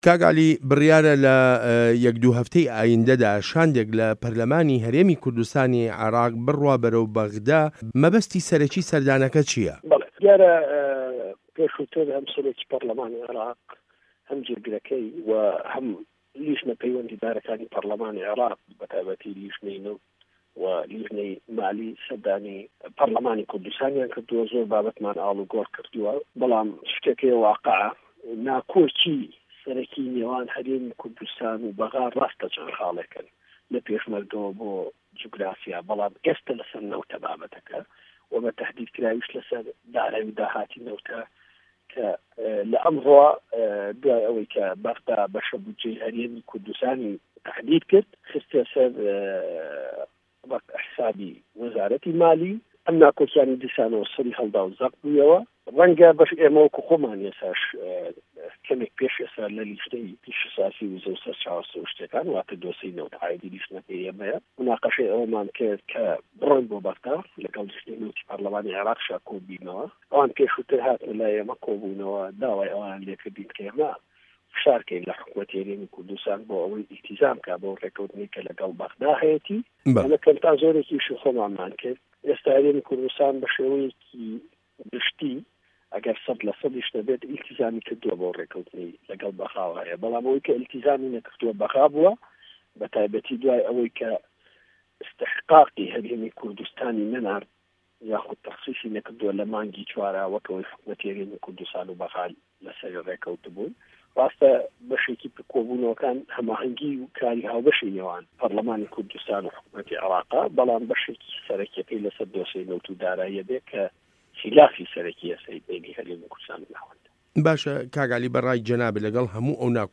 وتووێژ له‌گه‌ڵ عه‌لی حه‌مه‌ ساڵح